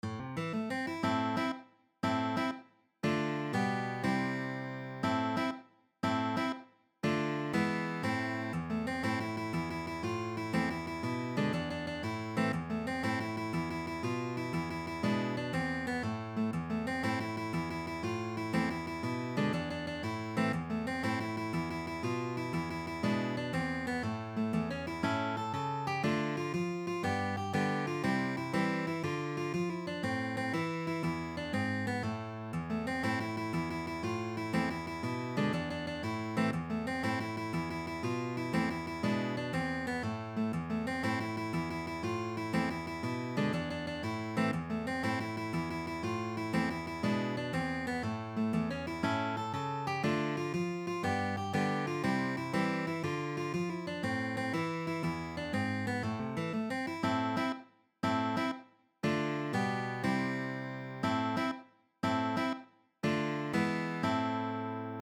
per sola chitarra
classica o acustica